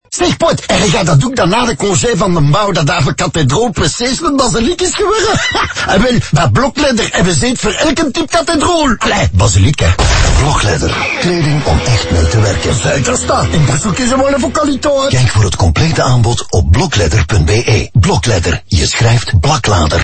Telkens worden scenario’s uitgewerkt waarin bouwvakkers in dialect met elkaar communiceren, van West-Vlaams tot Kempisch en Limburgs.
• Brussels
Brussels.mp3